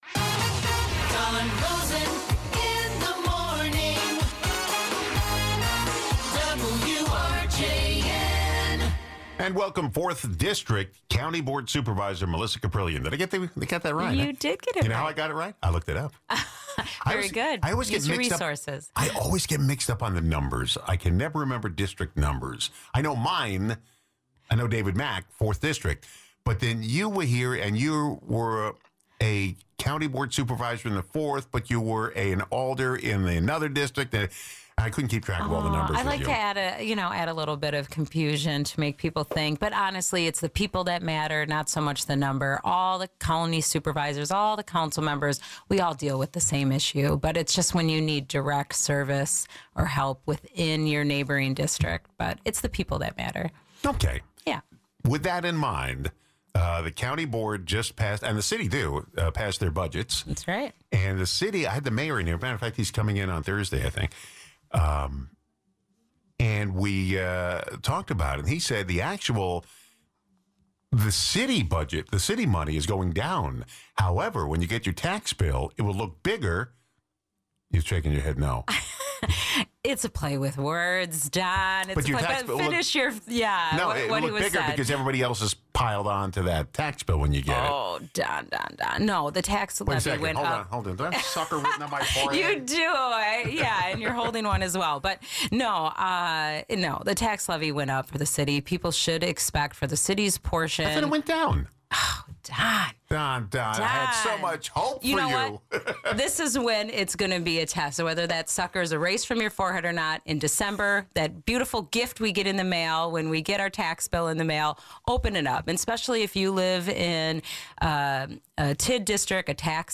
Fourth District County Board Supervisor Melissa Kaprelian talks budget, closing the Sturtevant PD, and other topics of interest.